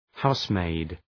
Προφορά
{‘haʋsmeıd} (Ουσιαστικό) ● υπηρέτρεια